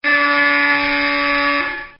Door Buzzer
Housing complex door buzzer like you would here in a large housing complex at the front door.